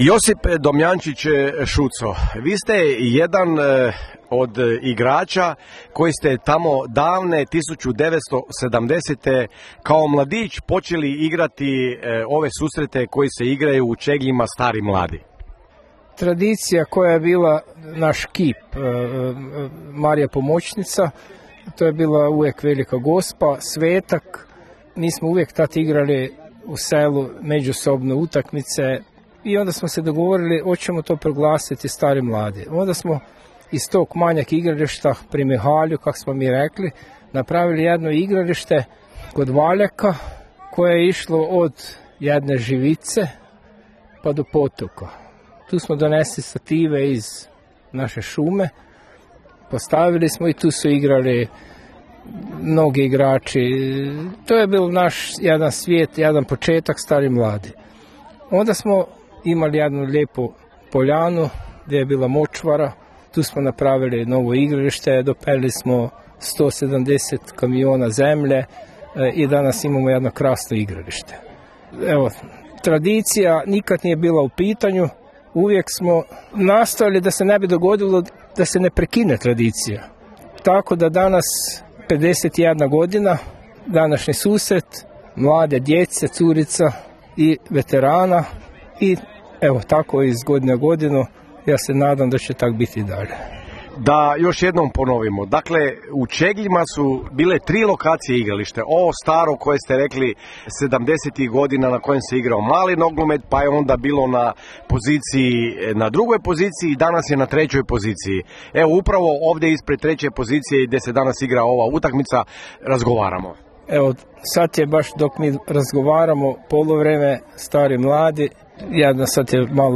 Bili smo u Čegljima na susretu starih i mladih